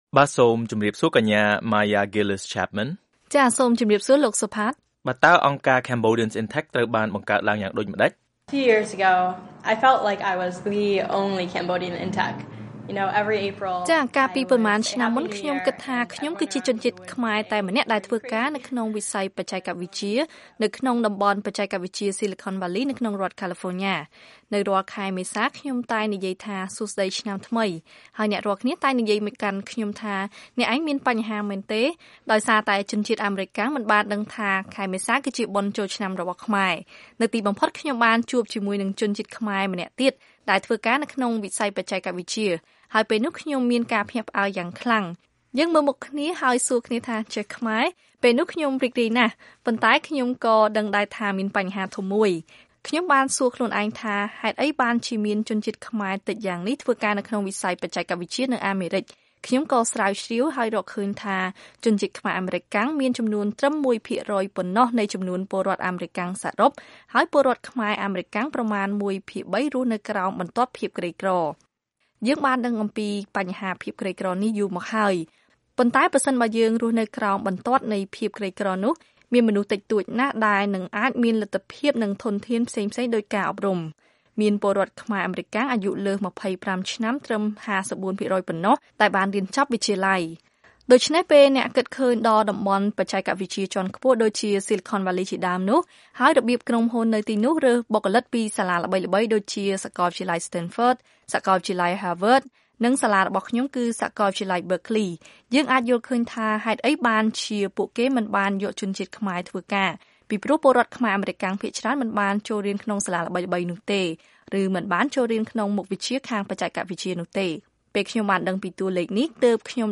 បទសម្ភាសន៍ VOA៖ អង្គការ Cambodians in Tech ចង់ភ្ជាប់ស្ពានបច្ចេកវិទ្យាអាមេរិកទៅកម្ពុជា